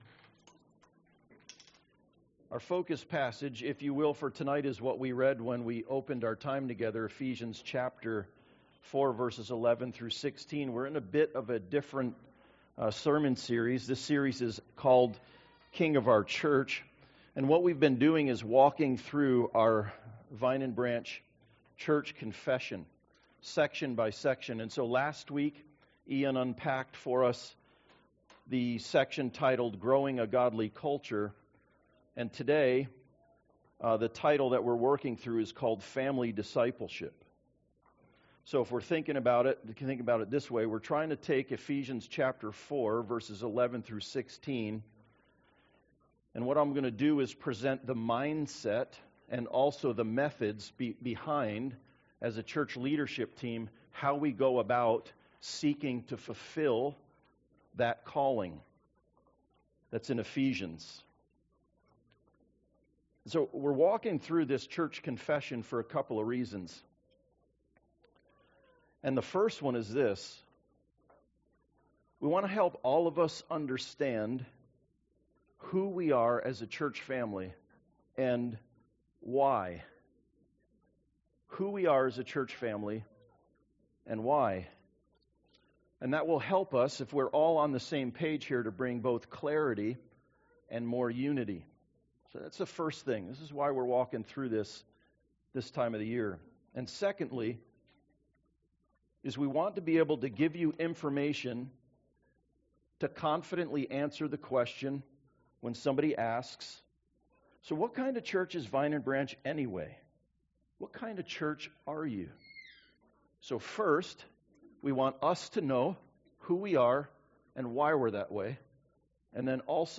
The King of our Church Service Type: Sunday Service Reasons we are walking through our Church Confession